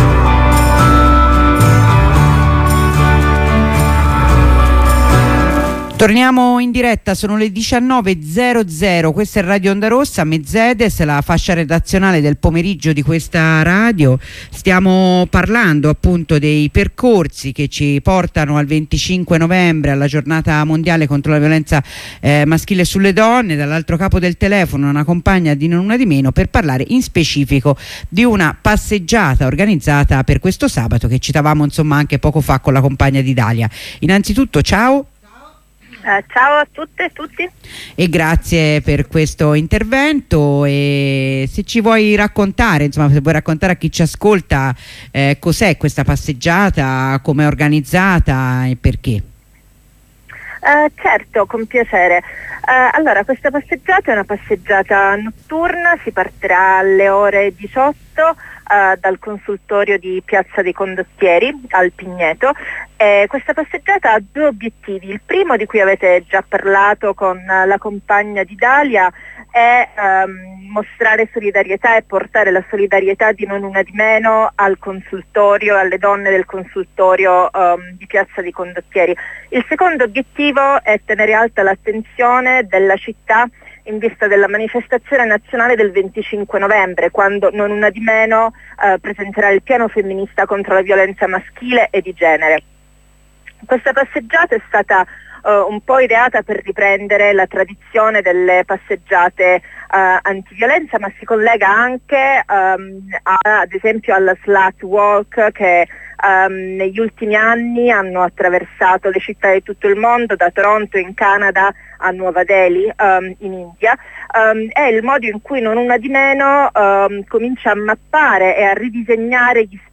Intervento di presentazione della manifestazione